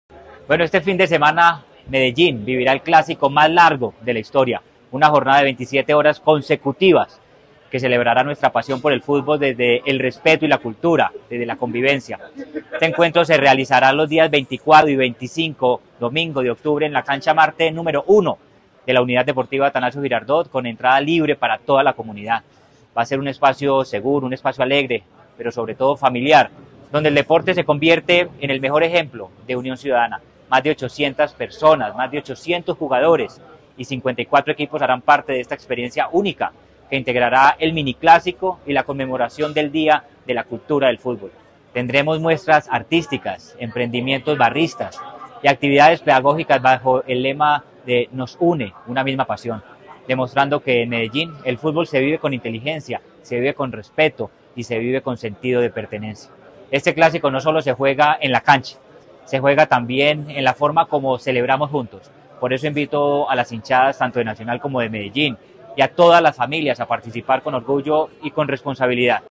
Declaraciones secretario de Seguridad y Convivencia de Medellín, Manuel Villa Mejía
Declaraciones-secretario-de-Seguridad-y-Convivencia-de-Medellin-Manuel-Villa-Mejia-1.mp3